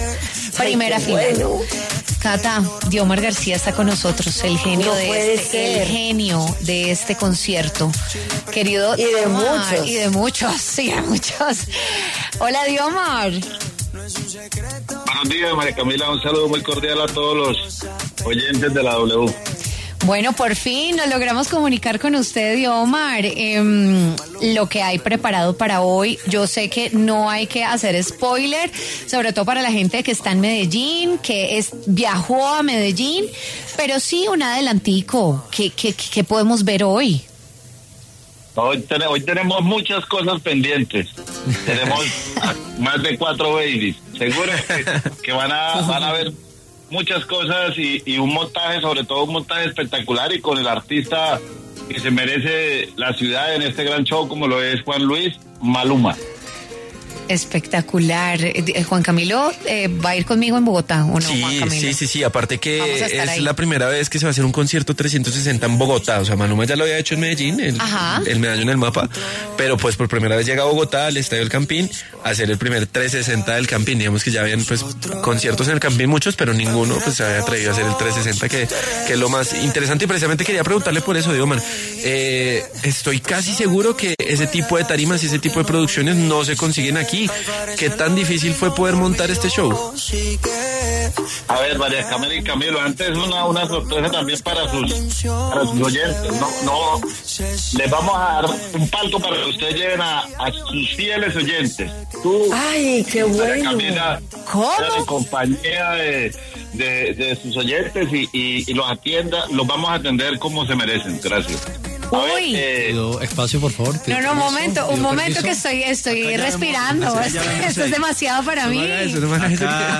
conversó con W Fin de Semana sobre lo que la gente puede esperar de las presentaciones de Maluma en Medellín y Bogotá.